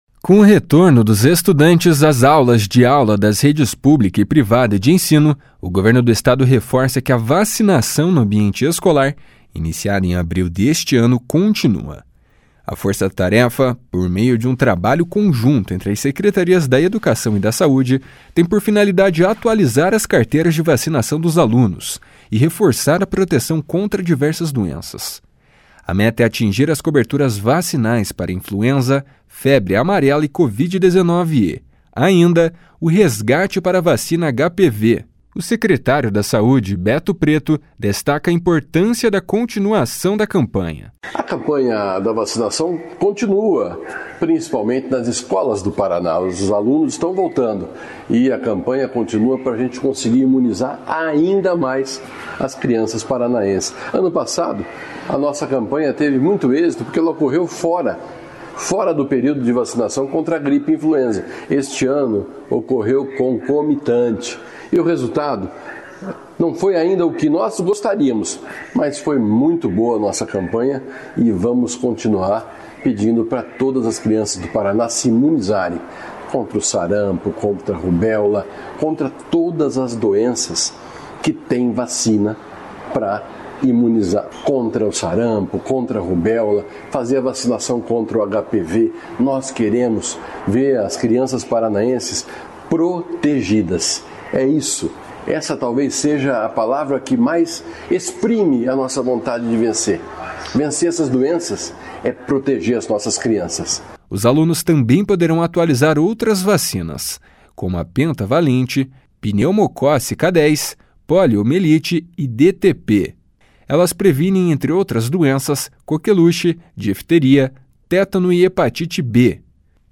O secretário da Saúde, Beto Preto, destaca a importância da continuação da campanha. // SONORA BETO PRETO //